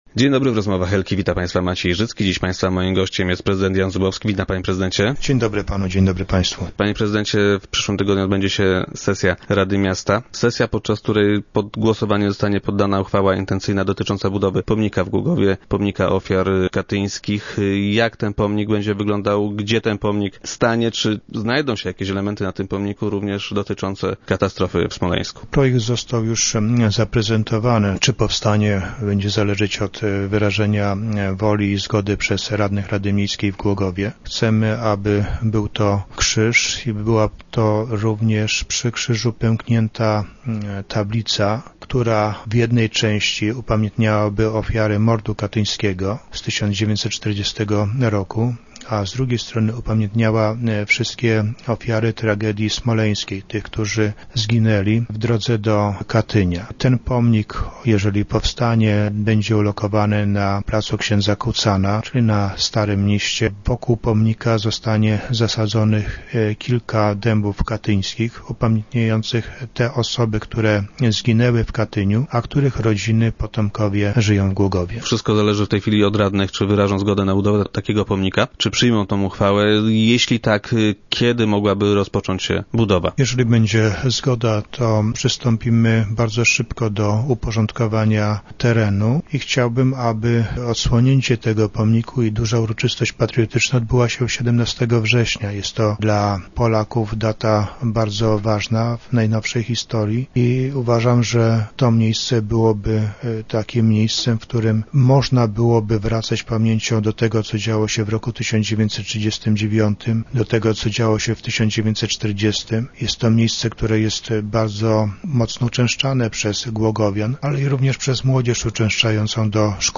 Jak powiedział prezydent Zubowski, który był dziś gościem Rozmów Elki, upamiętni on również ofiary katastrofy pod Smoleńskiem.